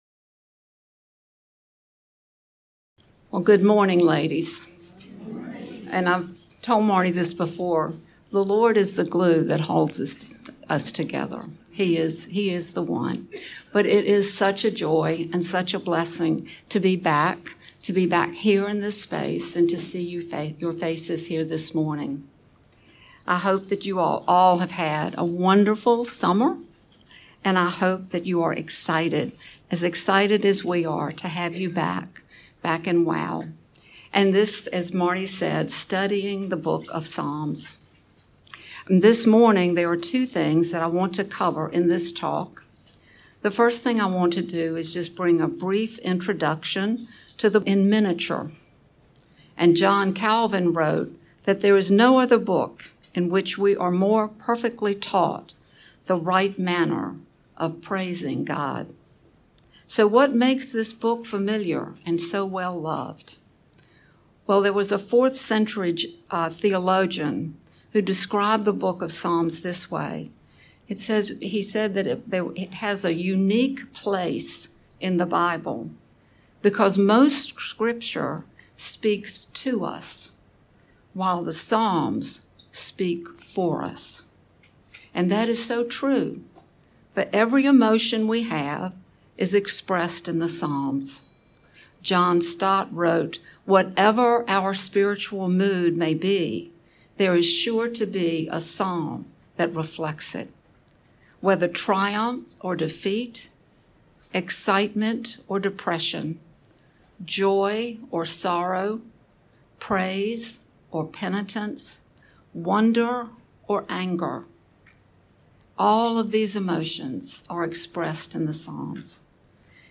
Women of the Word Wednesday Teaching Lesson 1: Psalm 1 Sep 14 2022 | 00:25:36 1x 00:00 / 00:25:36 Subscribe Share RSS Feed Share Link Embed